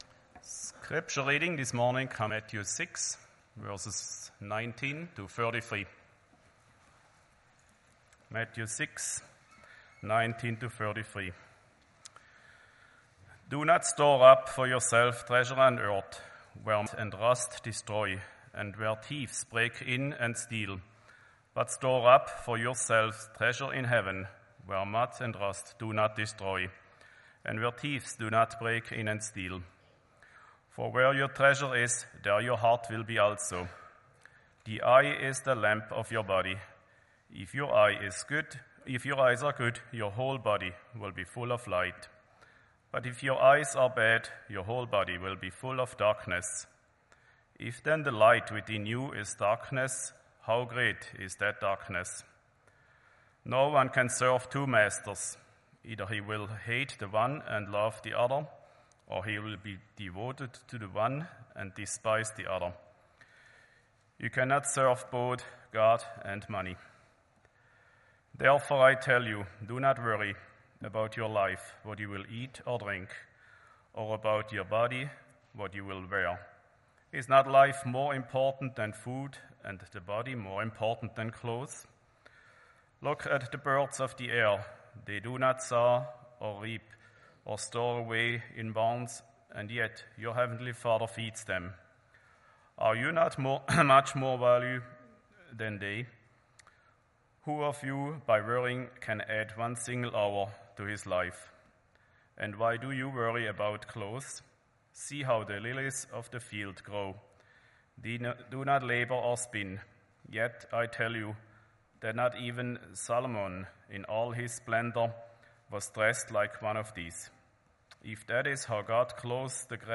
Sermons | Forest Baptist Church